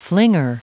Prononciation du mot flinger en anglais (fichier audio)
Prononciation du mot : flinger